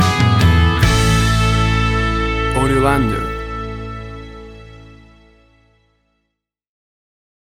WAV Sample Rate: 16-Bit stereo, 44.1 kHz
Tempo (BPM): 72